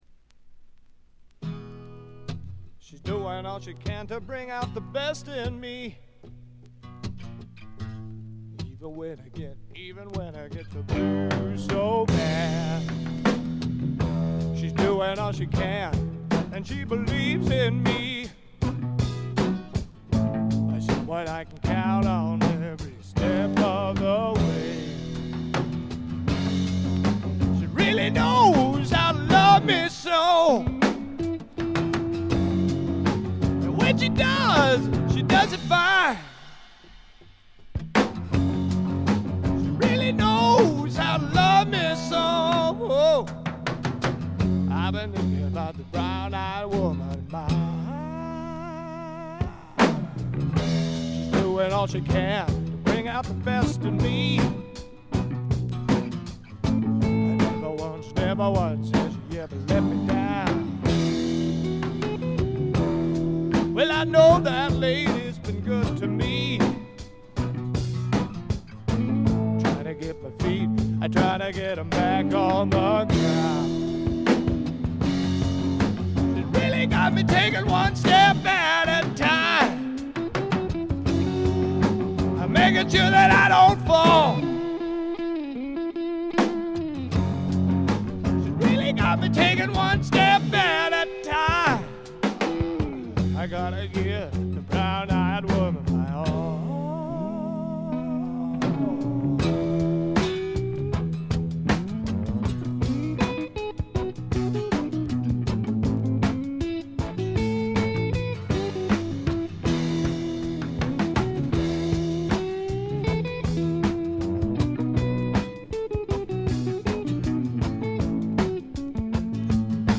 静音部で軽微なバックグラウンドノイズが少し。
乾ききった硬質感で統一された見事なスワンプロック！
試聴曲は現品からの取り込み音源です。